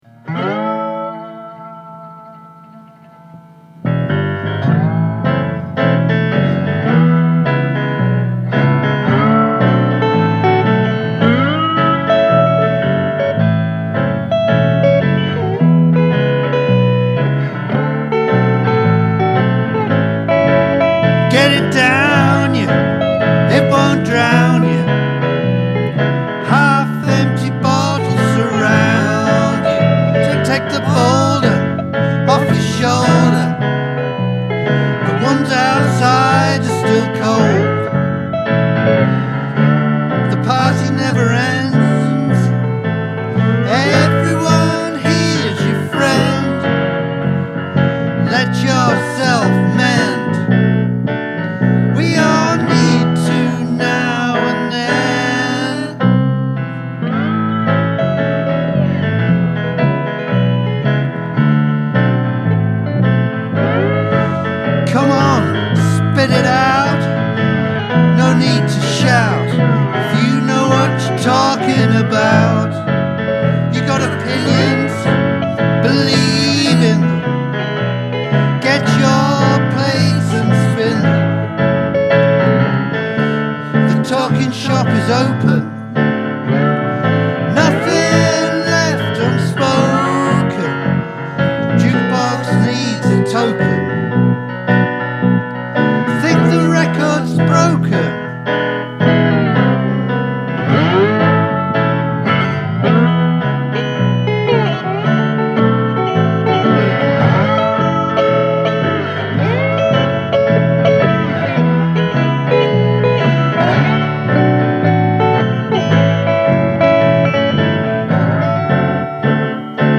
pot finger guitar
What a great drinking tune!